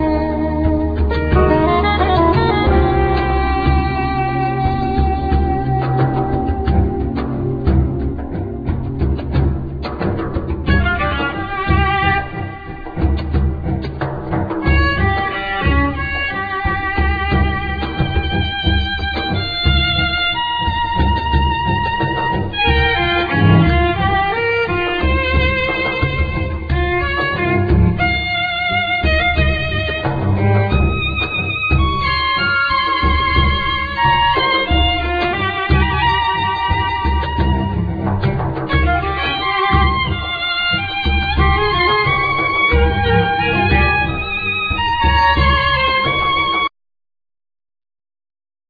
Tar,Riq,Bendir,Shakers,Caxixis,Bodhram
Cajon,Darhuka,Spanish Guitar,Bass Guitar
Viola,Violin